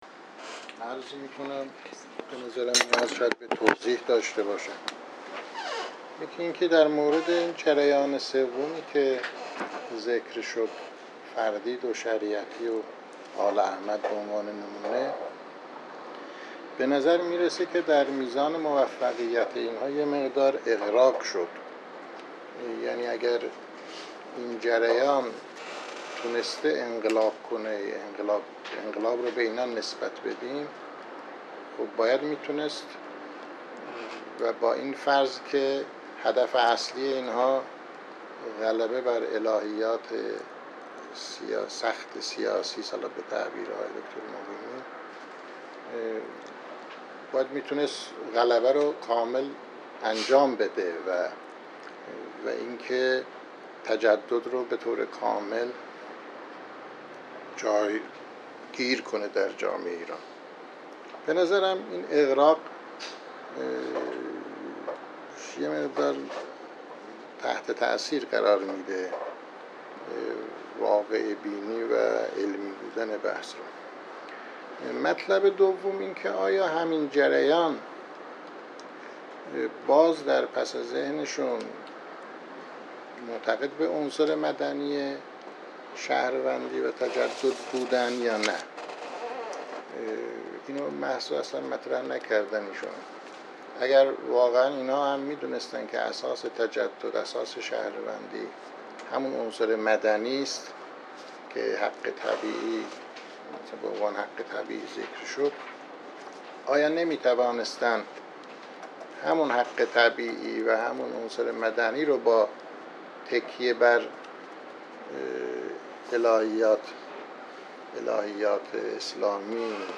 سالن ادب